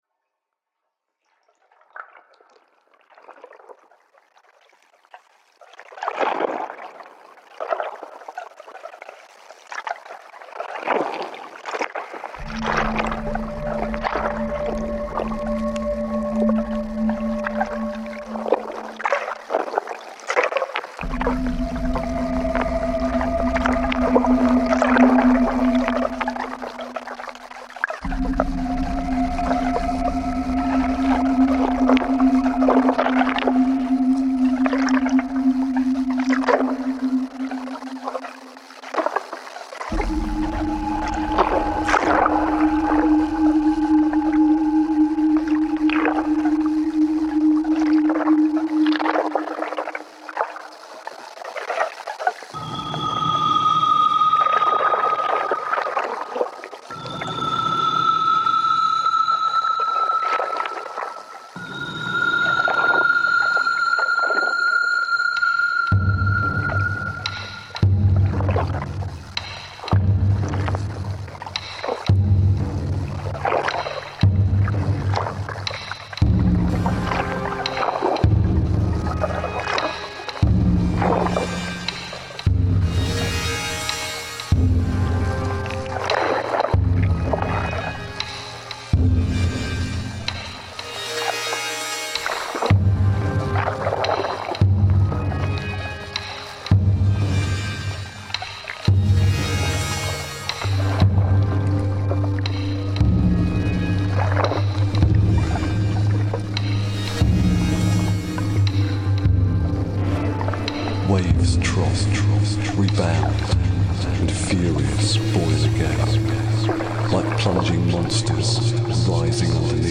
In the final clip of the poem, the words are eventually overwhelmed by The Flood.
Hakkeijima Island underwater sounds reimagined